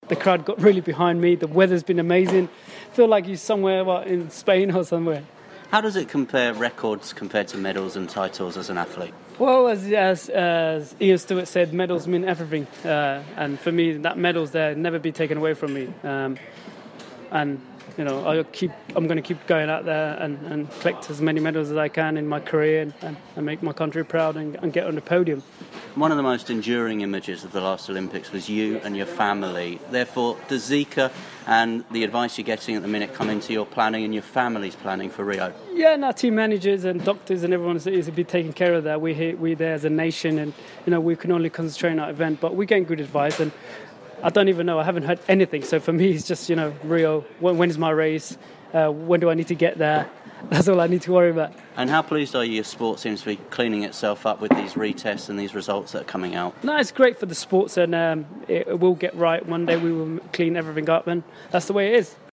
speaks to Mo Farah after the Team GB athlete smashed the 3000m record at the Diamond League in Birmingham